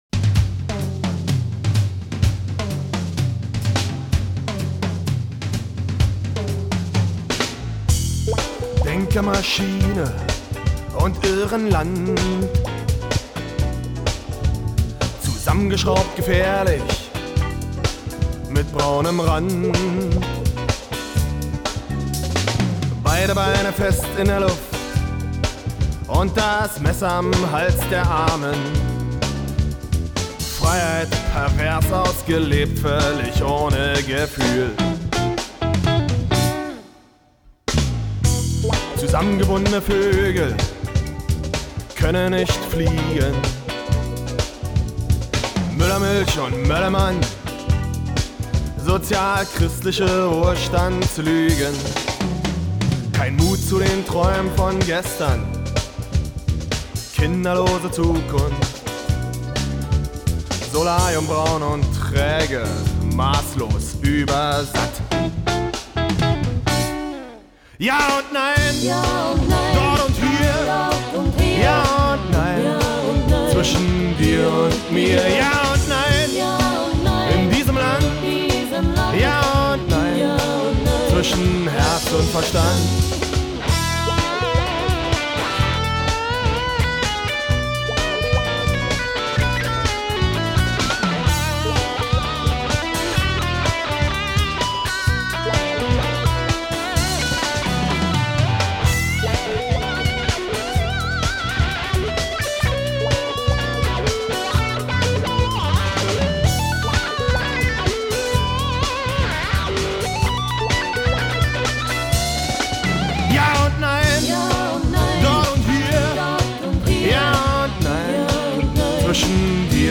Bass
Schlagzeug. Die Demos haben ein paar zauberhafte Kollegen mit eingespielt
die Gitarre
Keybords
Satzgesänge